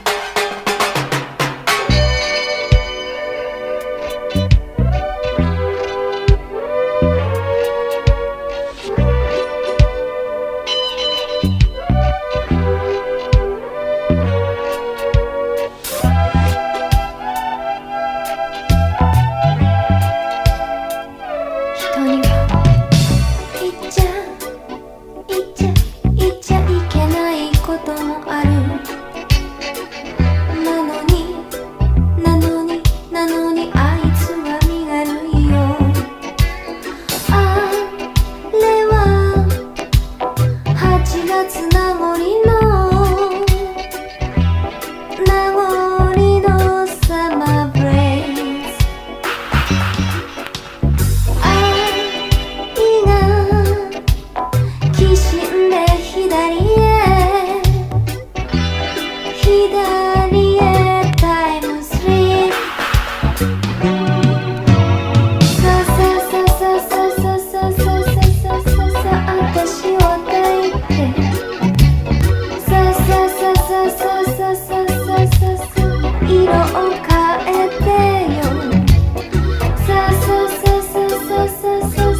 JAPANESE REGGAE
テクノ和レゲエ歌謡
80'S女性ヴォーカルの色気が満ちた10曲を収録。